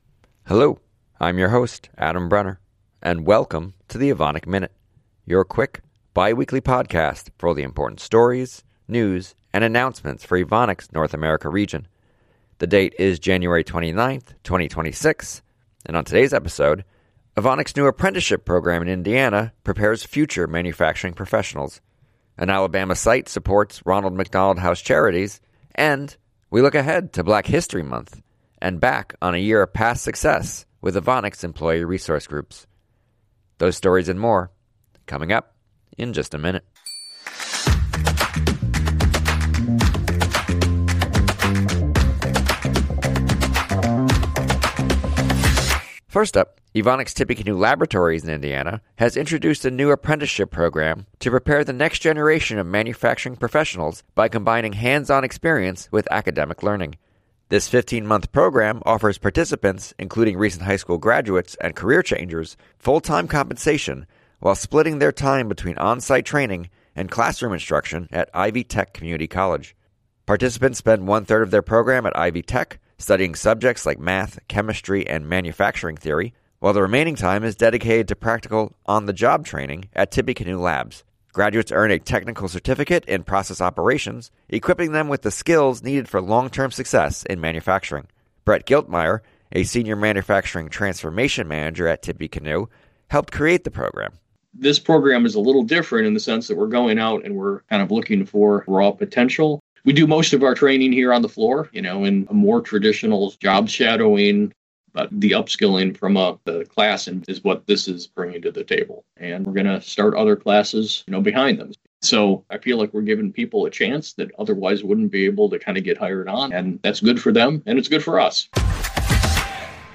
The Evonik Minute is the news podcast for Evonik's North America Region.